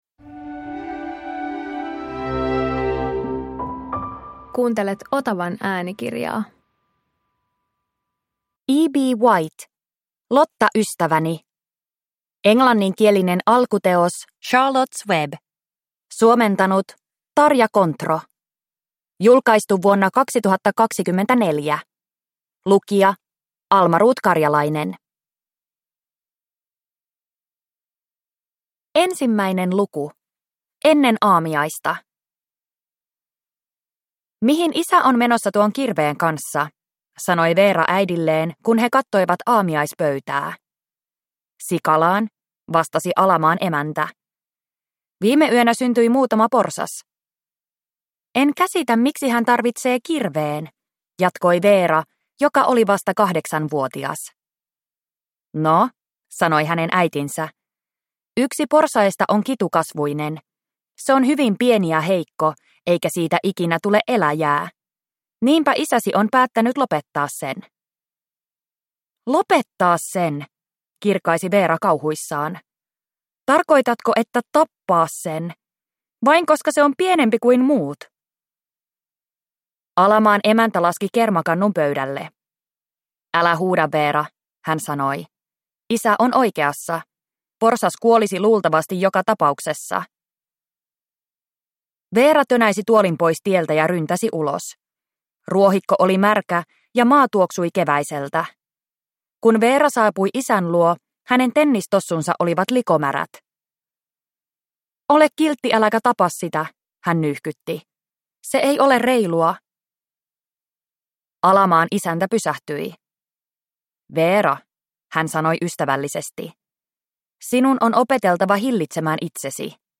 Lotta ystäväni – Ljudbok
Koskettava lastenkirjojen klassikko nyt äänikirjana!